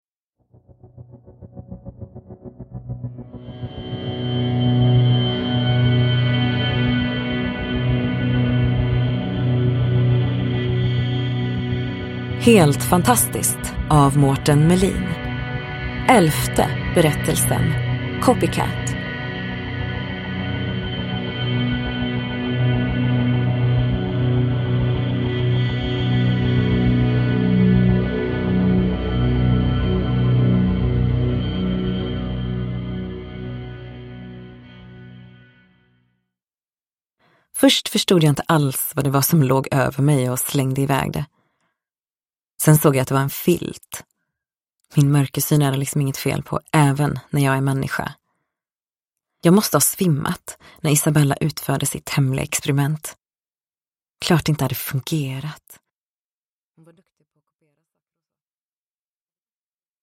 Copycat : en novell ur samlingen Helt fantastiskt – Ljudbok – Laddas ner